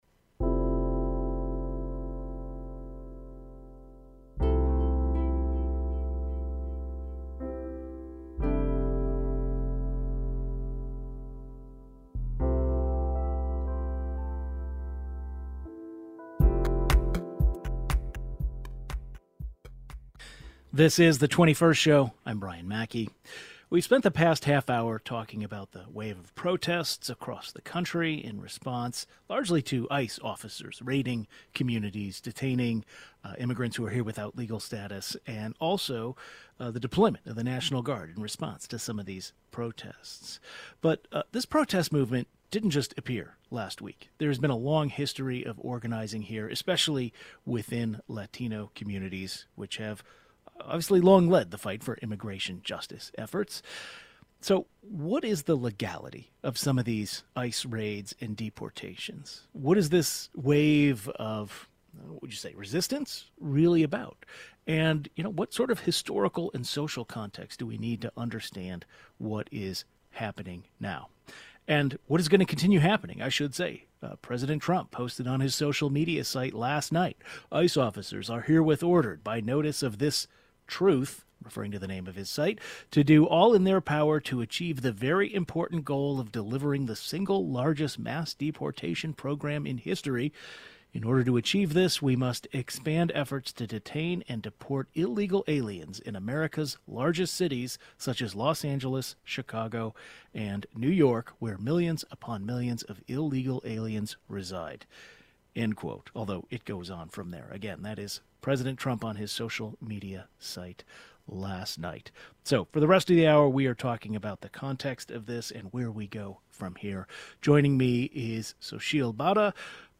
Immigration experts from the Latino community discuss the legality of these ICE raids and deportations and what historical and social context is needed to understand this moment.